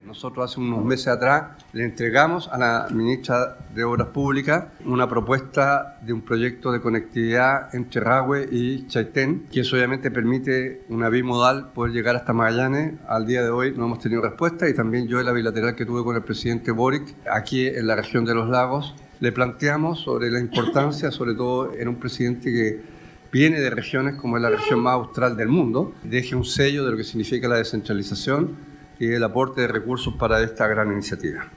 gobernador-los-lagos.mp3